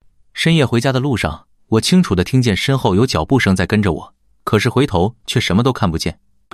Neutral3.mp3